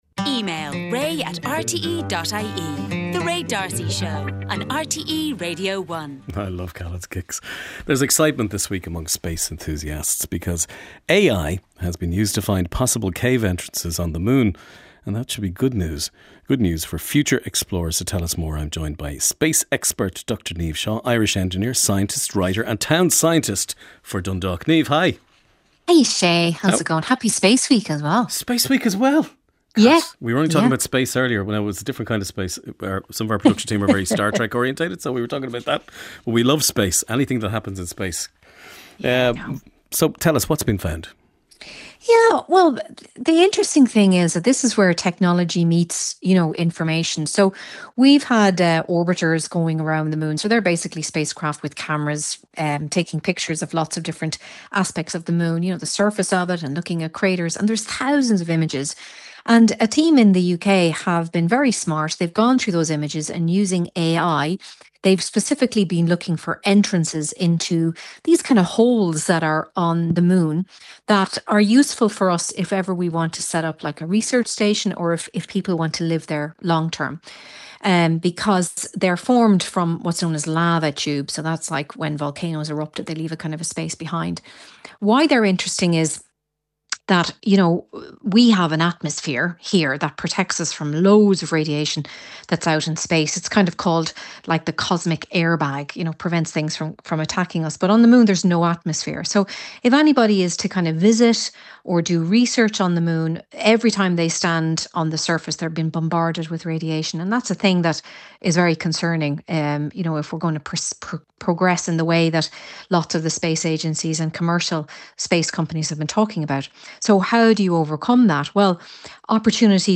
space expert